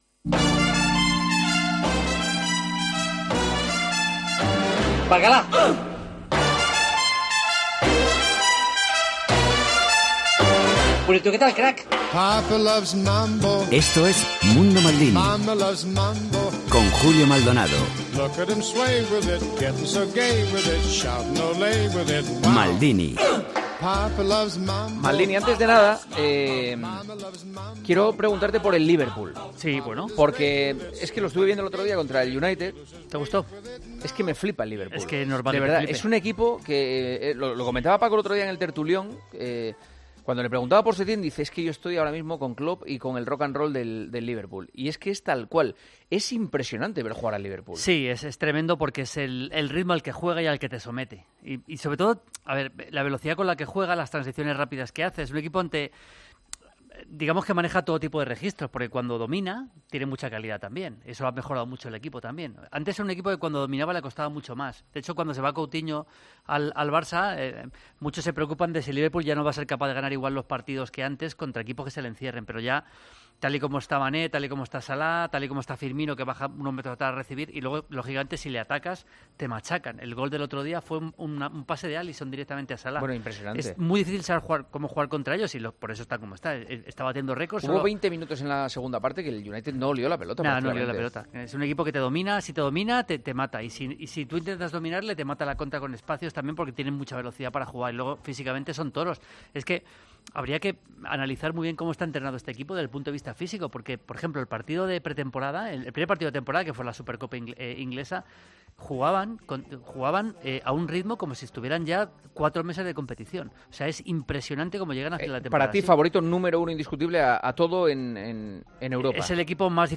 AUDIO: Maldini habla de brasileños que han jugado en el Madrid, jugadores destacados del fin de semana y responde a las preguntas de oyentes.